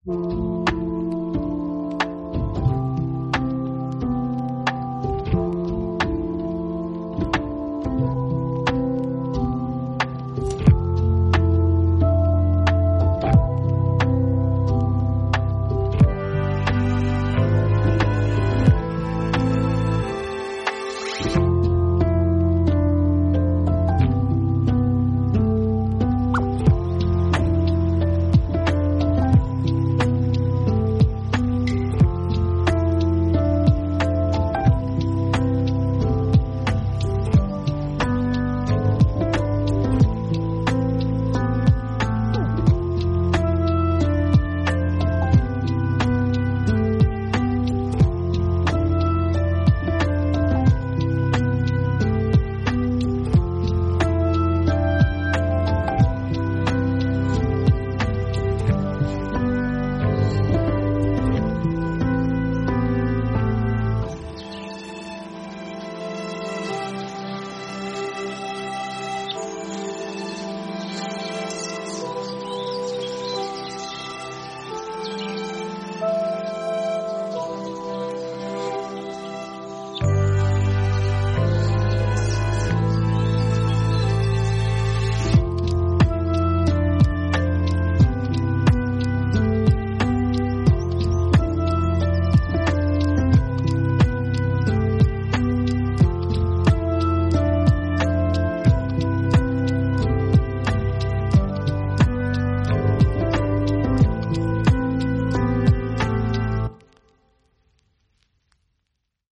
calming track